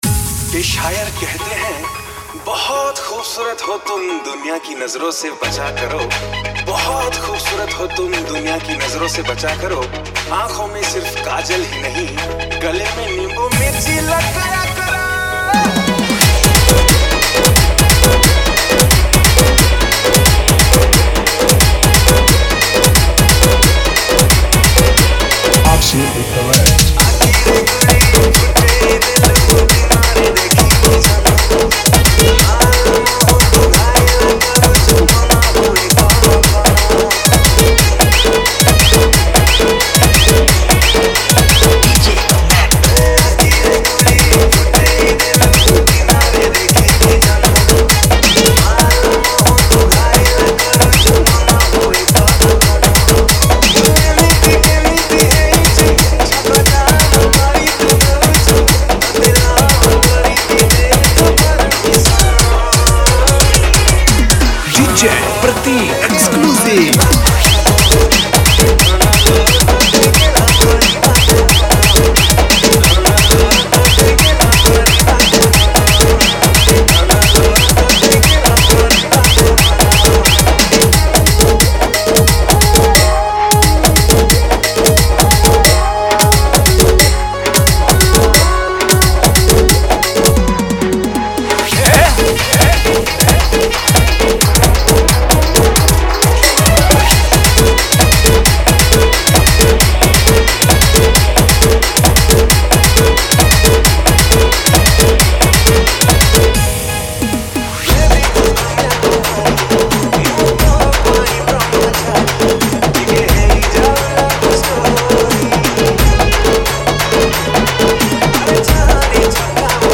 Category:  New Odia Dj Song 2021